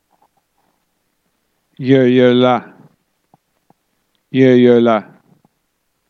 Meditazione Vibrazionale
Guardate il Nome, ponete il dito sul nome ed ascoltate il nome.